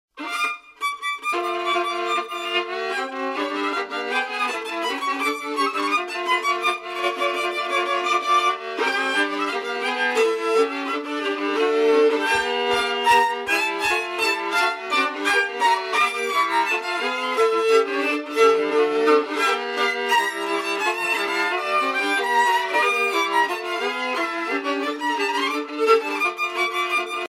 danse : csárdás (Hongrie)
Pièce musicale éditée